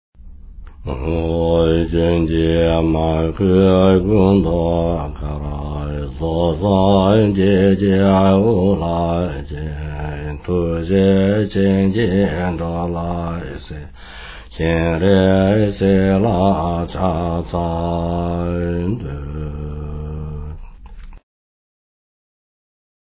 Om Mani Padme Hum - Kyabje Lama Zopa Rinpoche - slow A
Om Mani Padme Hum - Kyabje Lama Zopa Rinpoche - slow A.mp3